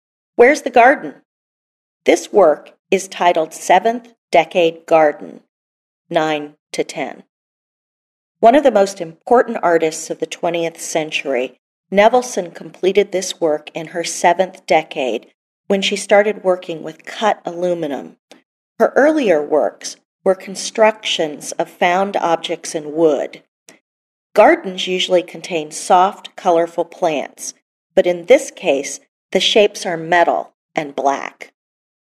Audio Tour – Ear for Art